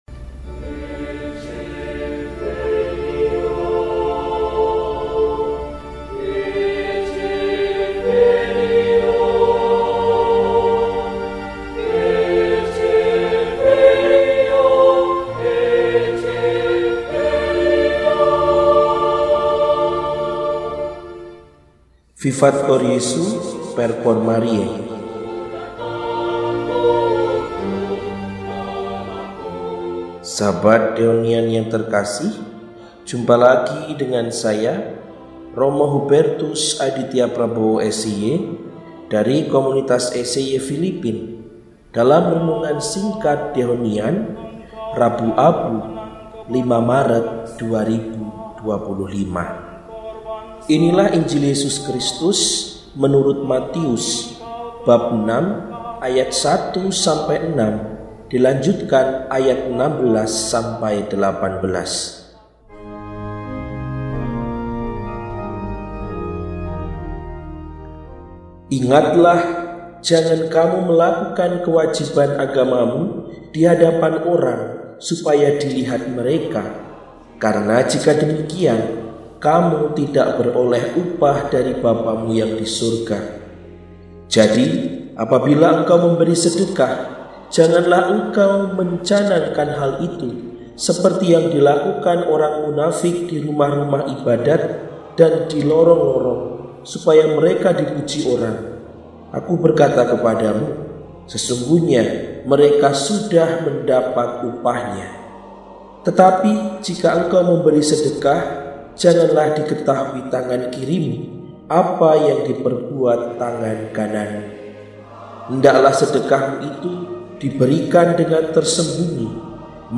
Rabu, 05 Maret 2025 – Hari Rabu Abu – Hari Puasa dan Pantang – RESI (Renungan Singkat) DEHONIAN